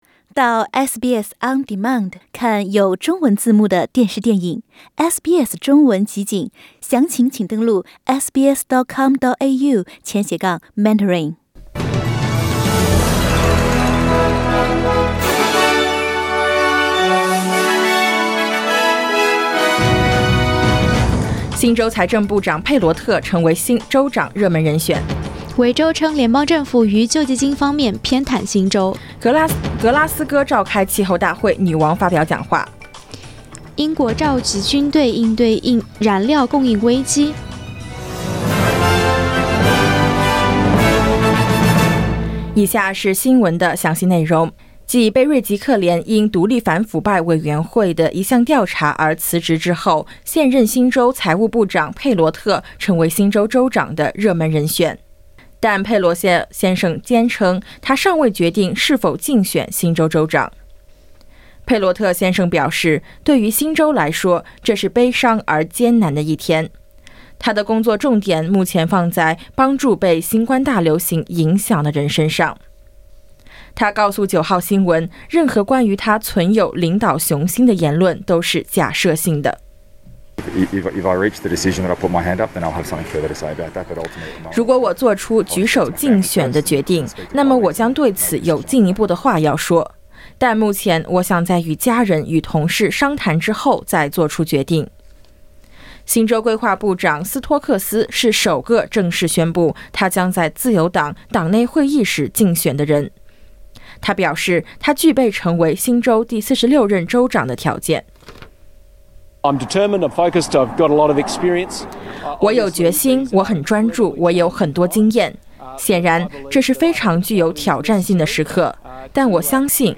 SBS早新闻（10月3日）
SBS Mandarin morning news Source: Getty Images